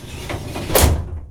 File Cabinet Close.wav